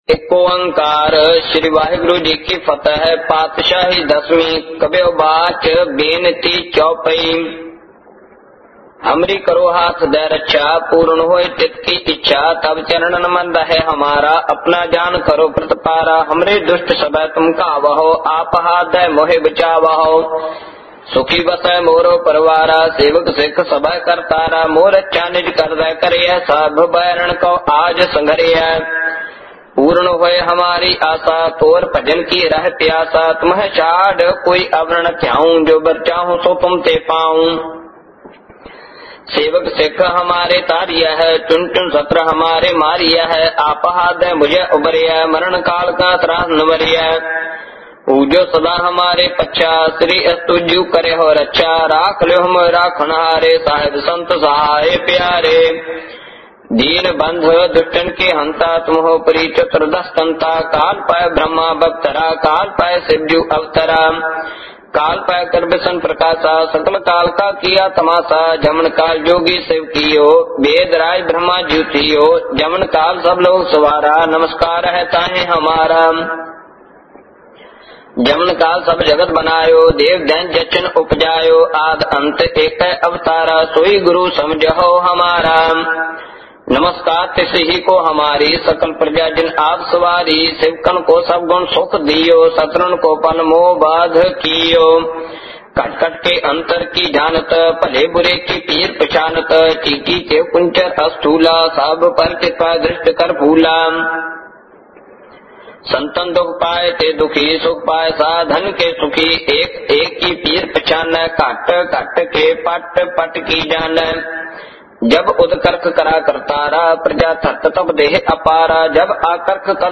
Genre: -Gurbani Ucharan